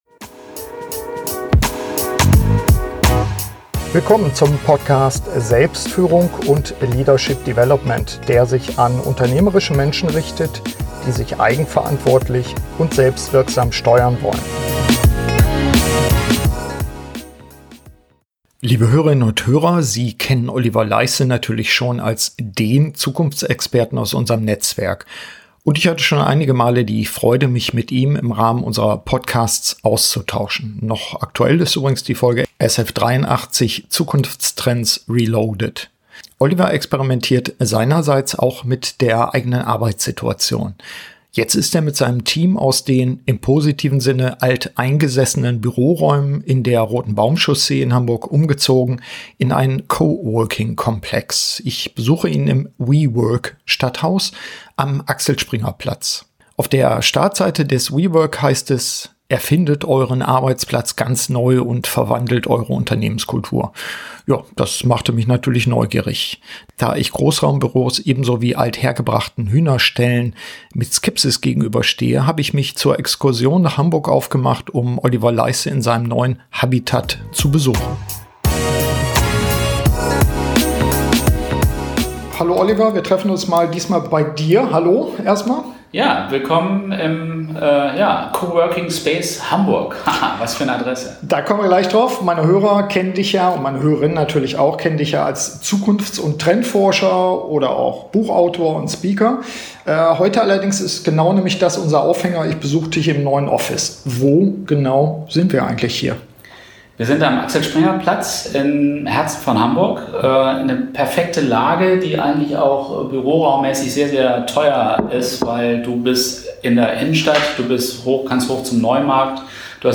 hat den Schritt getan und ich befrage ihn zu seinen ersten Erfahrungen.